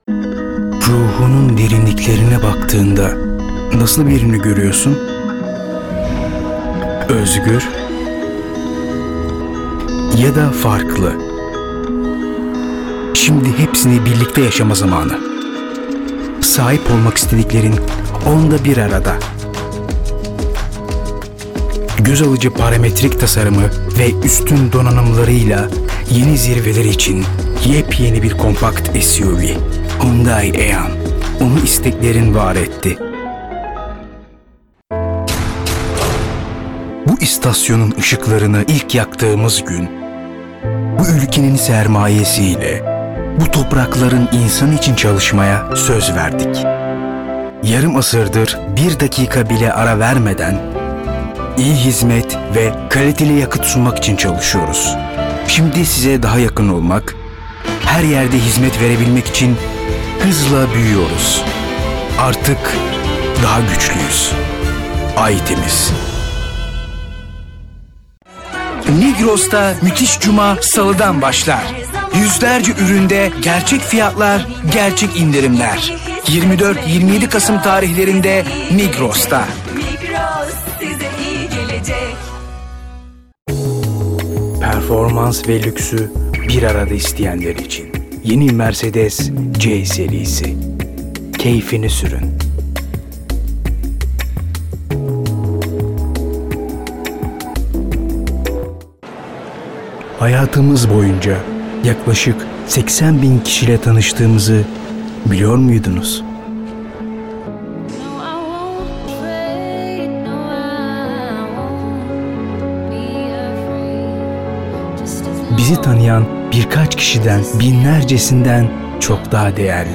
Native speaker Male 30-50 lat